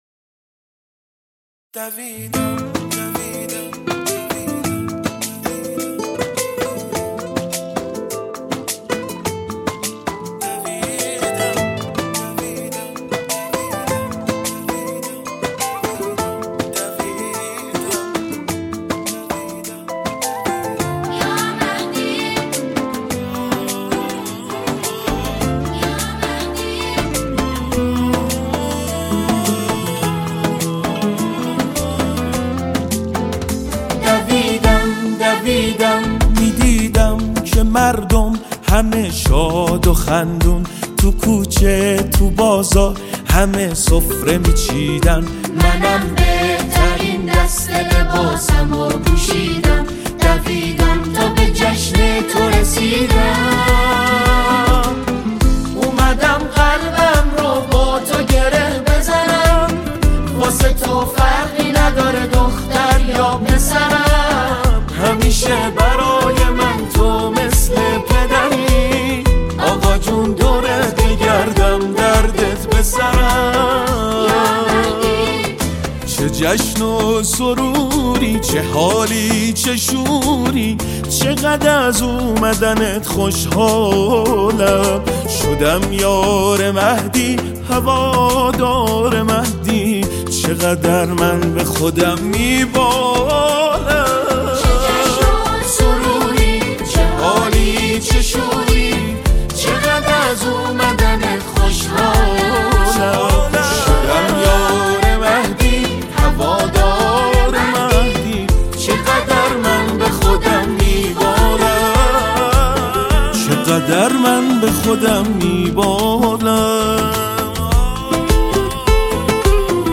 صدای گرم
ژانر: سرود ، سرود کودک و نوجوان ، سرود مناسبتی